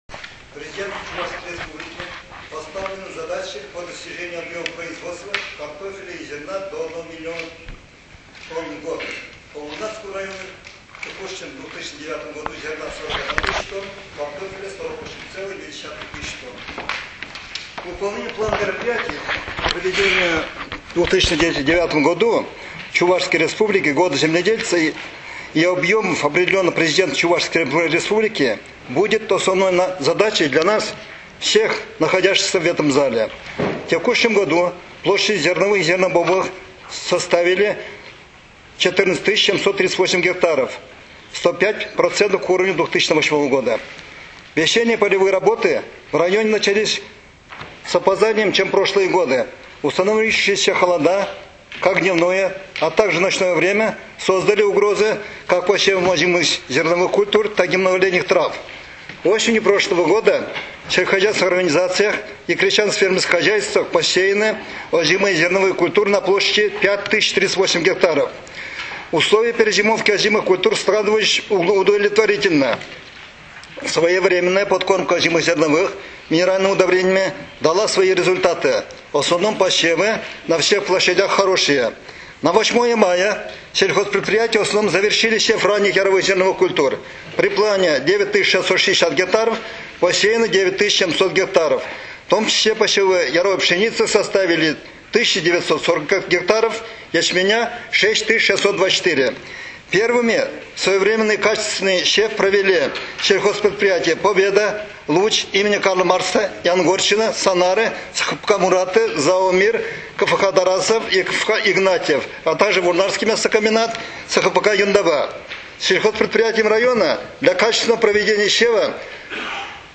Вопрос о реализации Указа Президента Чувашии Николая Федорова «О Годе земледельца» был главным на заседании Собрания депутатов Вурнарского района и вызвал бурное обсуждение.
Первый заместитель главы Вурнарского района – начальник отдела сельского хозяйства и экологии Александр Борисов подробно изложил в своем докладе ситуацию, сложившуюся в отрасли сельского хозяйства района (аудиозапись выступления) . Он отметил, что в текущем году площади зерновых и зернобобовых составят 14738 гектаров - 105 % к уровню 2008 года.